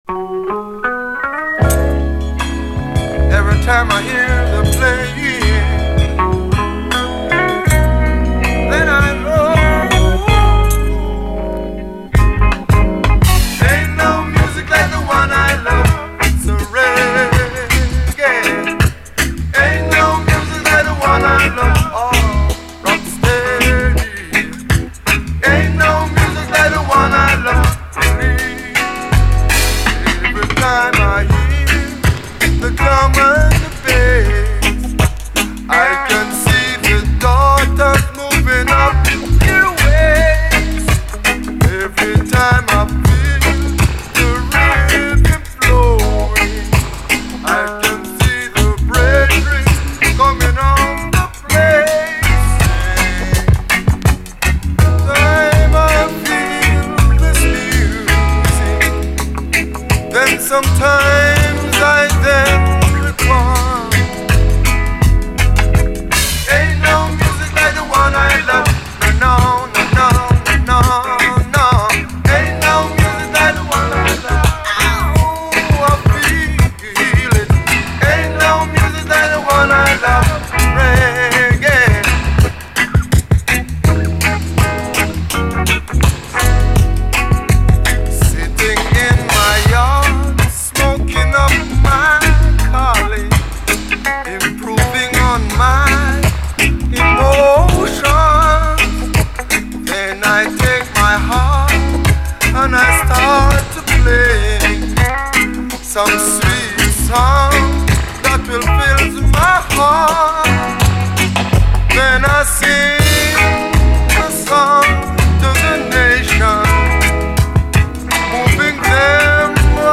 SOUL, 70's～ SOUL, REGGAE
試聴ファイルはこの盤からの録音です
スモーキーな哀愁とレゲエならではの懐の深いファンクネスが最高です。後半にはダブに接続！